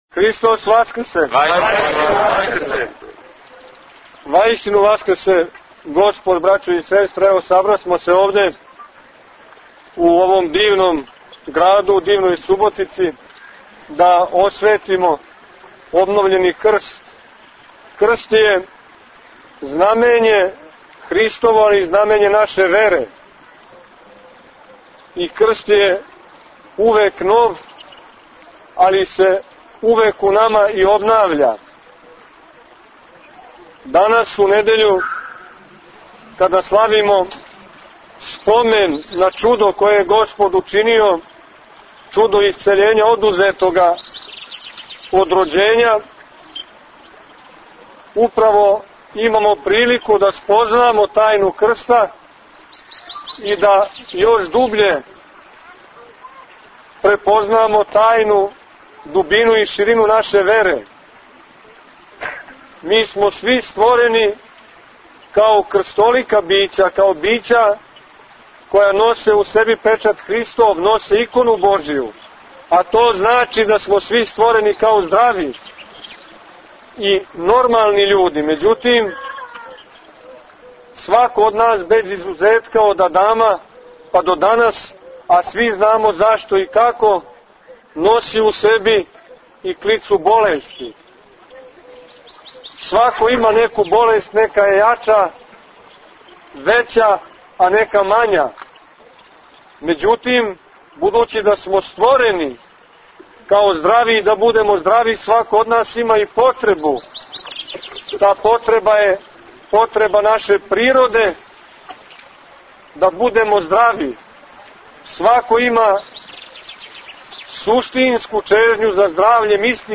У четврту недељу по Пасхи, 25. априла текуће године, извршено је освећење и подизање крста на звоник Световазнесенског храма у Суботици.
Беседа Епископа Порфирија
Osvecenje i Podizanje krsta - Beseda Vladika Porfirije.mp3